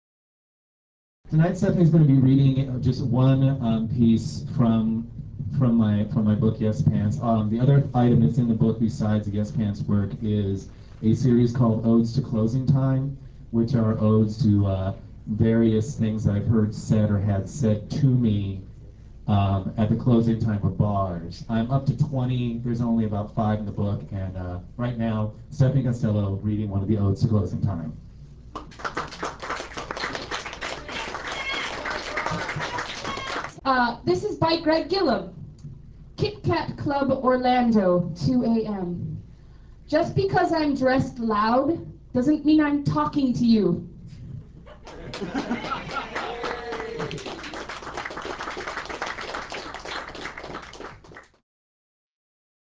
Live - Quimby's